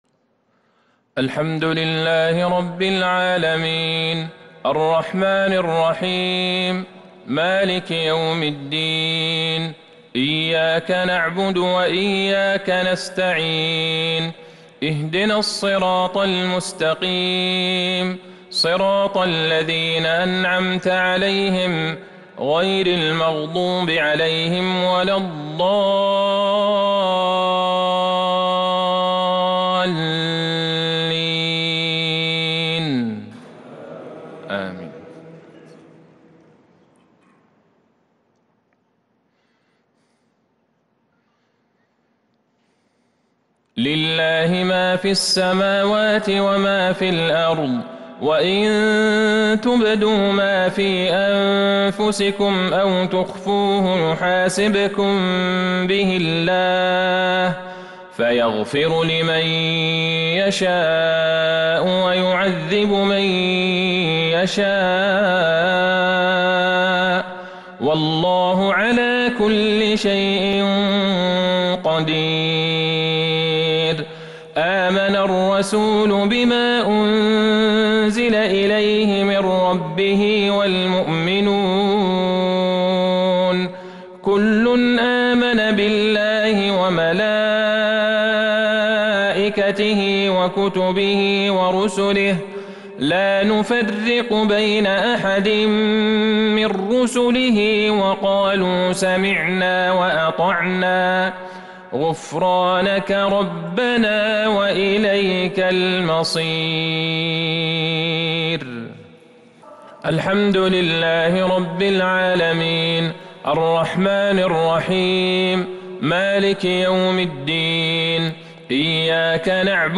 صلاة المغرب للقارئ عبدالله البعيجان 6 ذو الحجة 1442 هـ
تِلَاوَات الْحَرَمَيْن .